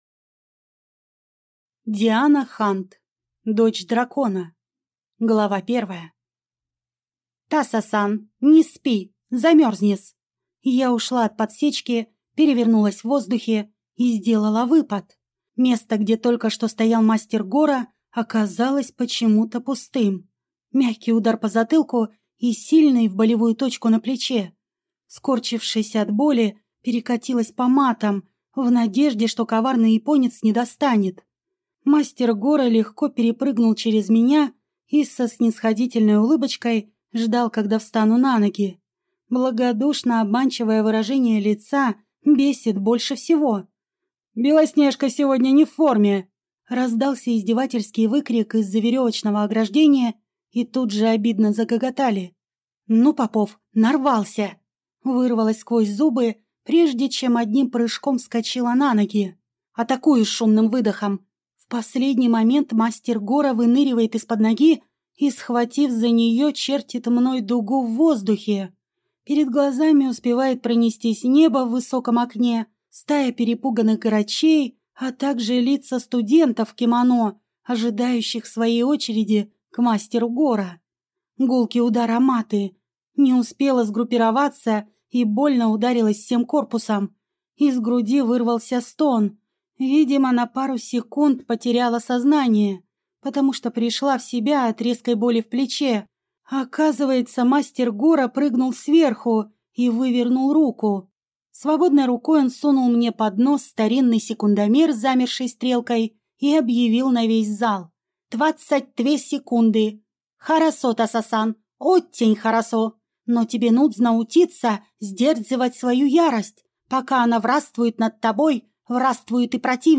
Аудиокнига Дочь дракона | Библиотека аудиокниг